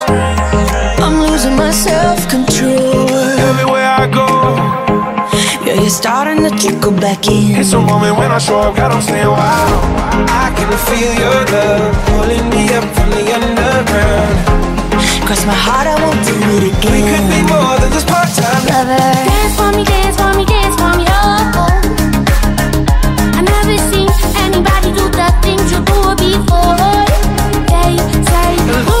English Ringtones